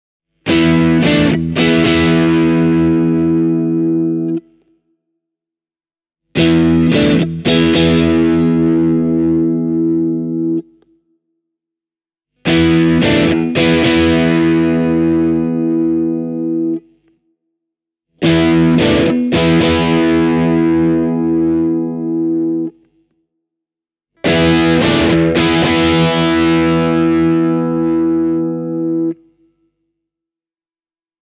The improved control set-up works just like it should – there’s a healthy dose of top end airiness in the bridge humbucker, while the single coils sound chiming, but fat.
fender-am-std-shawbucker-e28093-overdriven.mp3